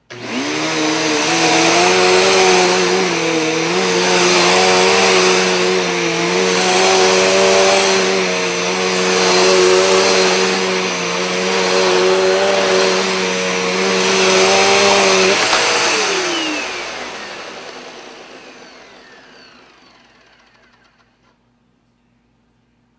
描述：我做的使用吸尘器的Foley录音，
Tag: 清洁器 真空